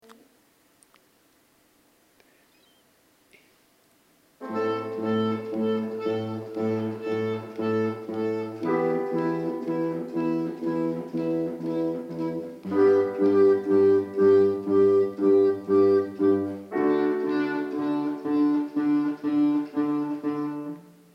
Clarinete en Si bemol
Guitarra
Violín
Piano
- Tonalidad: Mi menor
Interpretaciones en directo.
Intro con piano
Intro_con_piano.MP3